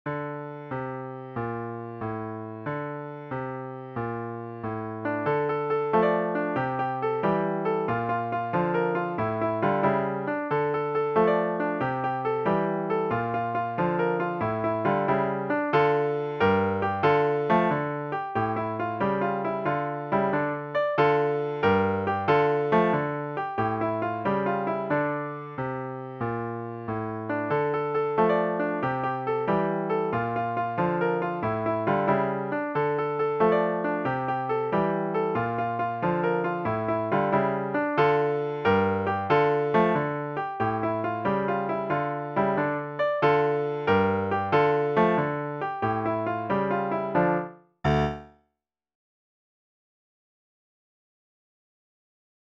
Easy/Level 3 Piano Solo